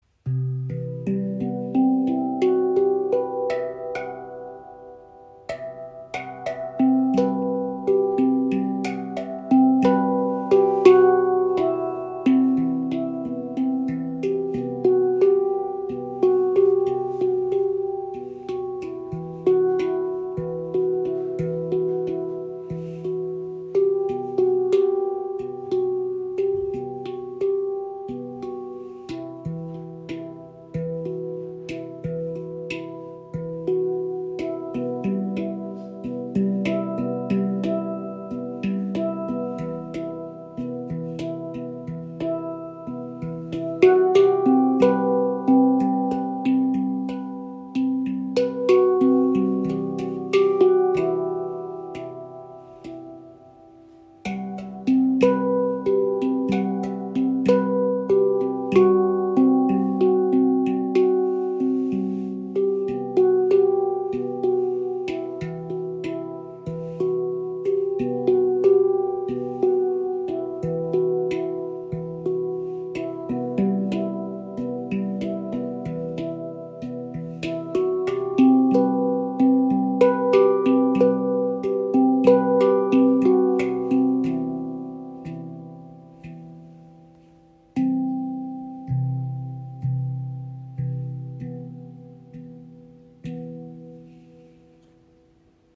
• Icon C-Aegean Stimmung – spielerisch und offen (C – E G B C E F# G B (E F#))
• Icon Edelstahl-Handpan – langes Sustain, klare Obertöne und voller Ausdruck
Tiefe, tragende Basstöne verbinden sich mit klaren Höhen zu einem organischen Gesamtklang.
Stainless Steel Handpan | Mercury | C-Aegean | 11 Töne
Handgefertigt aus Edelstahl entfaltet diese C-Aegean-Handpan einen warmen, fliessenden Klang – ideal für erzählerisches Spiel, ruhige Improvisationen und meditative Klangreisen.
Ihr Charakter ist warm, fliessend und ausgewogen.